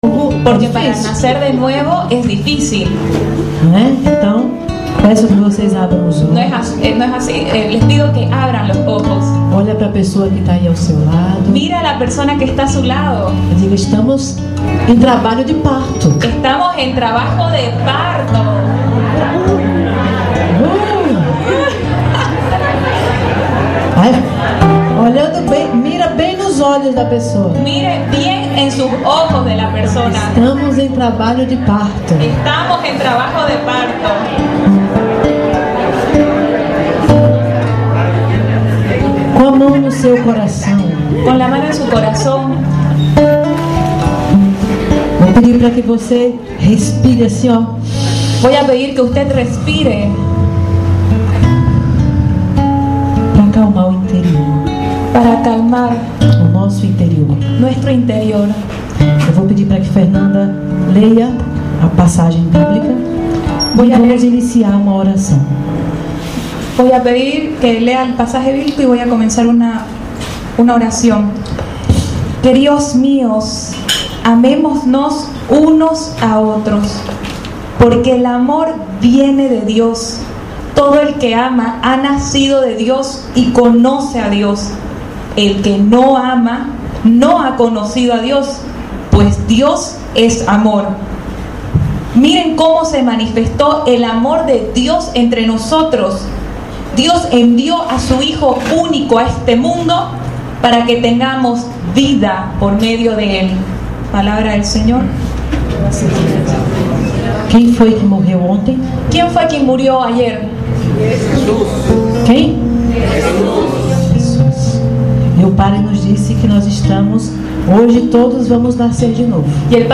Tiempo de oración y prédica